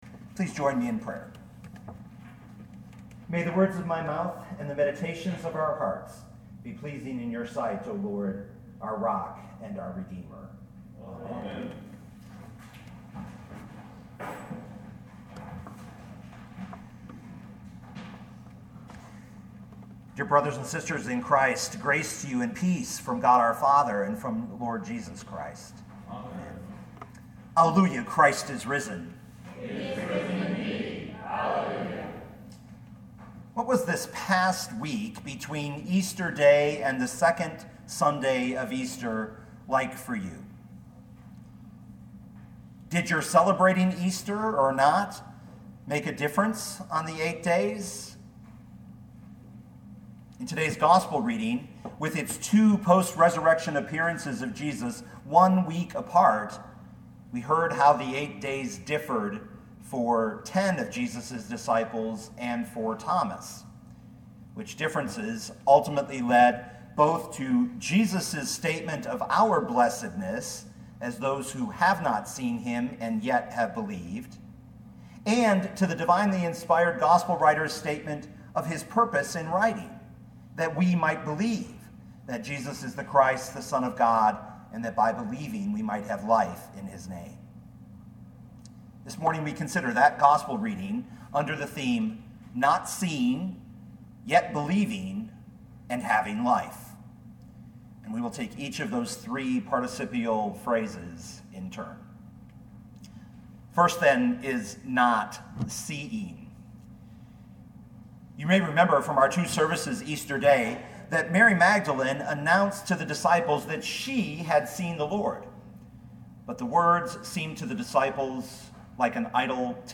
2019 John 20:19-31 Listen to the sermon with the player below, or, download the audio.